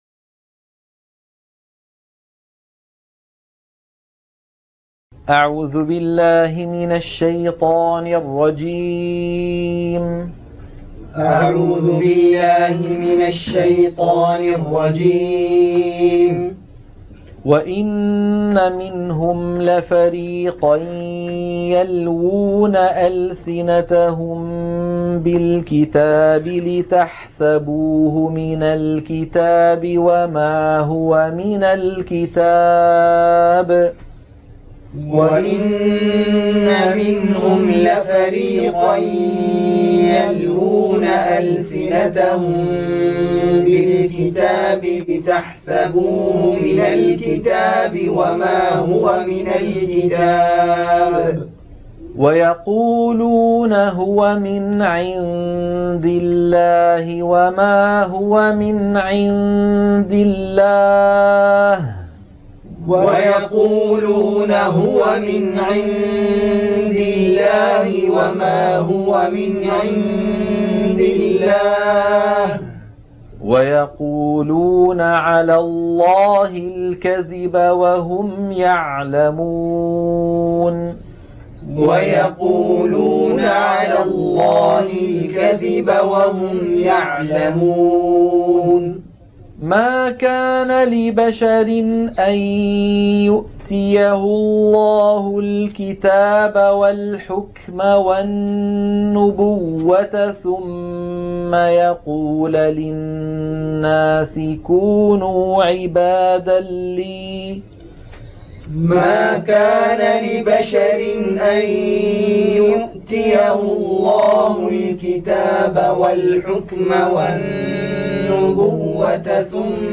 تلقين سورة آل عمران - الصفحة 60 التلاوة المنهجية - الشيخ أيمن سويد